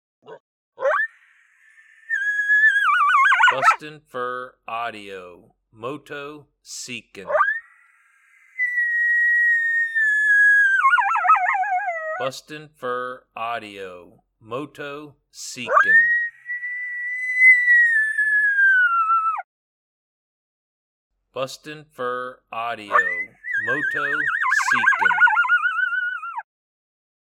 BFAs MotoMoto lonely and howling in response to vocal coyotes.
• Product Code: howls